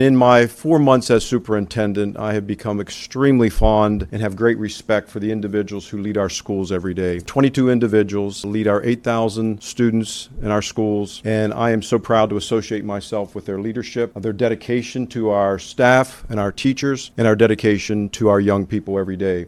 October is Principal Appreciation Month, and at the most recent Allegany County Board of Education meeting, Superintendent Dr. Michael Martirano recognized all of the County’s elementary, high school and the career center principals.